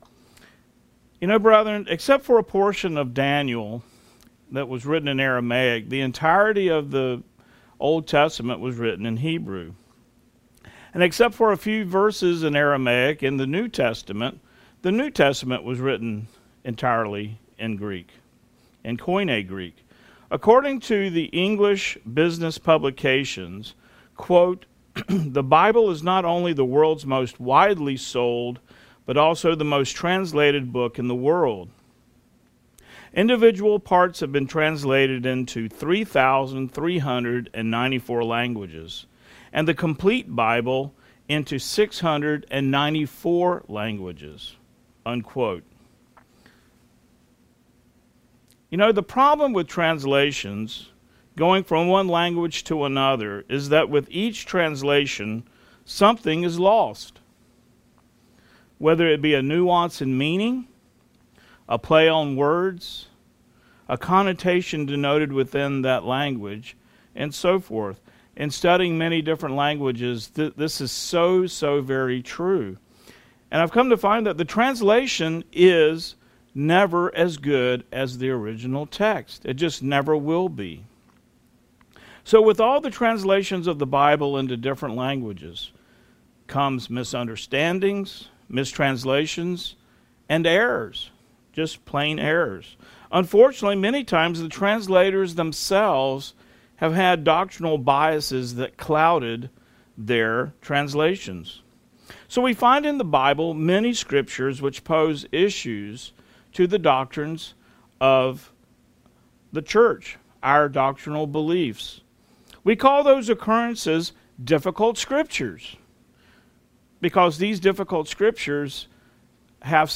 New Sermon | PacificCoG
From Location: "Houston, TX"